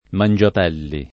[ man J ap $ lli ]